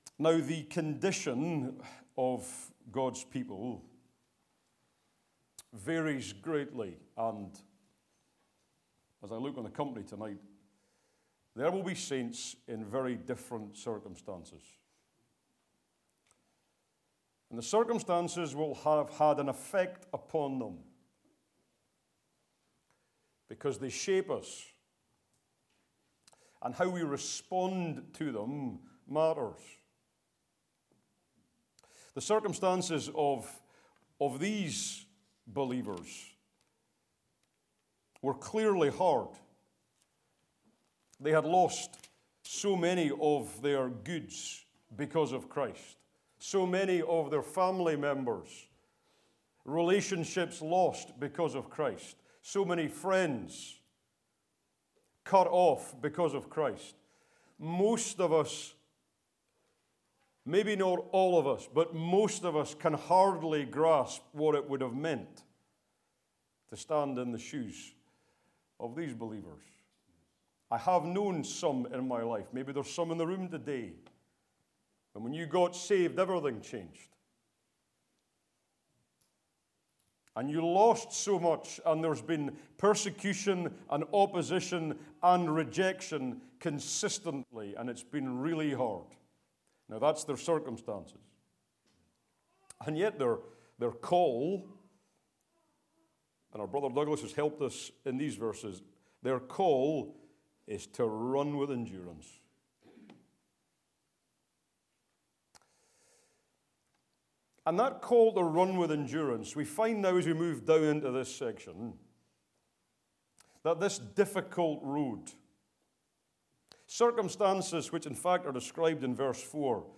2026 Easter Conference